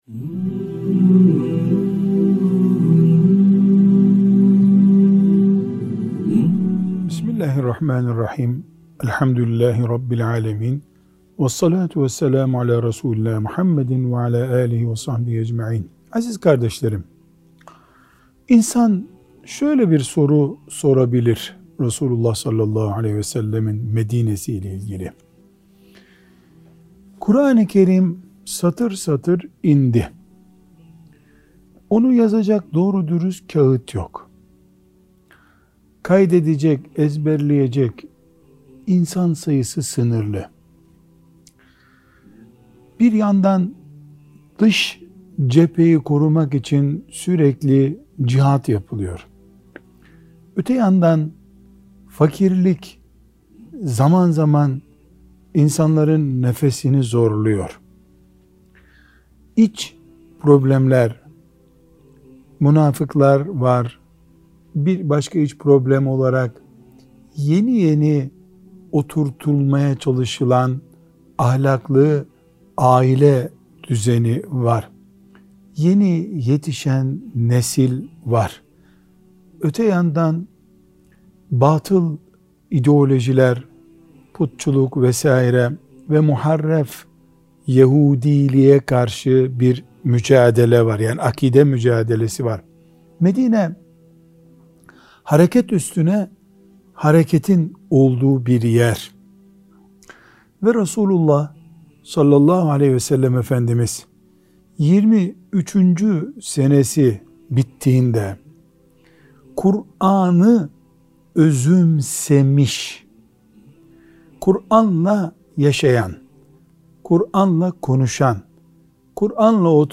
1. Sohbet Arşivi